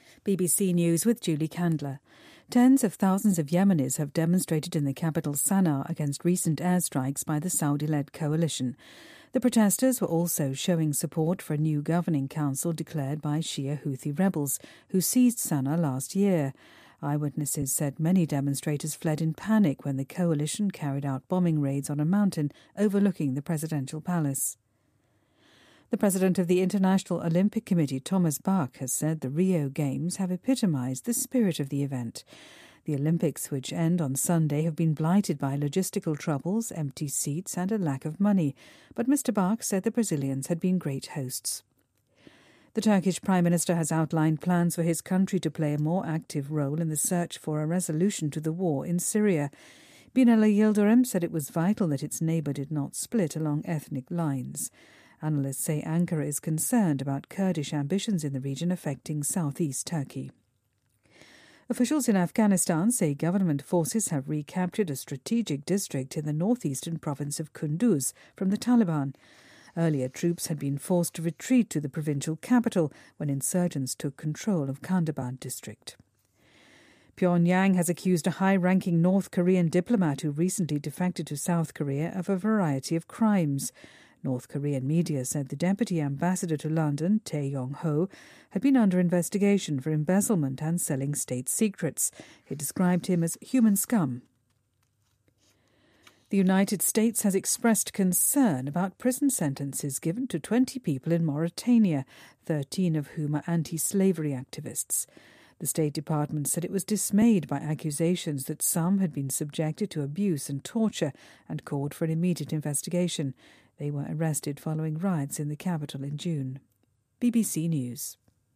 BBC news,平壤高级外交官叛逃韩国